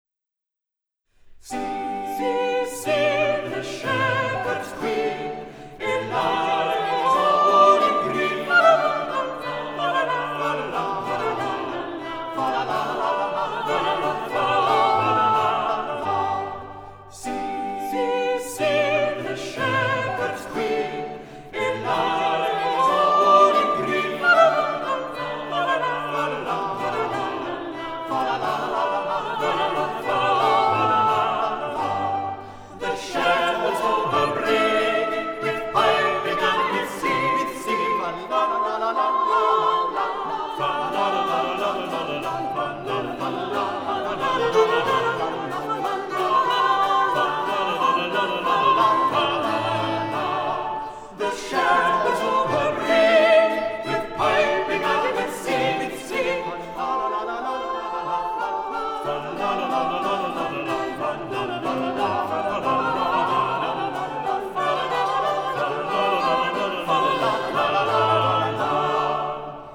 古典音樂